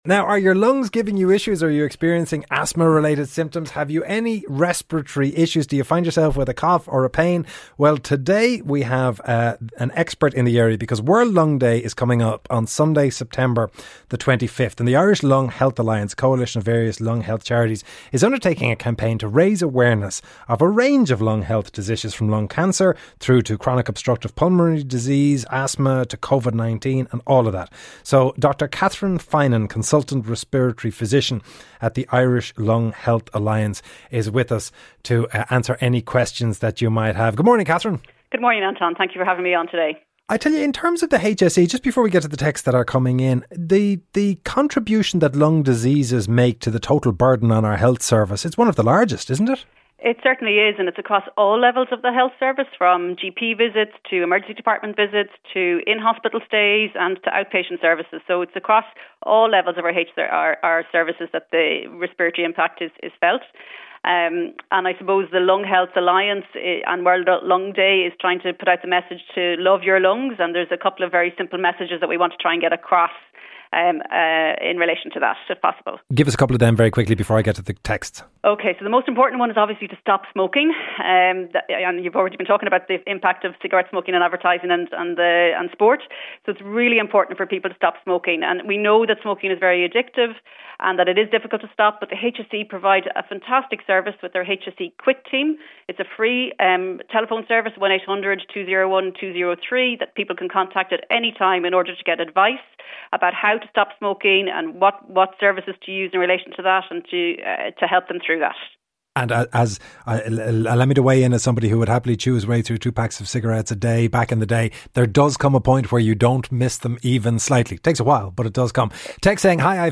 reports from London ahead of the new UK Prime Minister being revealed